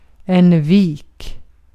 Ääntäminen
Synonyymit golf Ääntäminen Haettu sana löytyi näillä lähdekielillä: ruotsi Käännös Ääninäyte Substantiivit 1. bay US 2. gulf US 3. cove Artikkeli: en .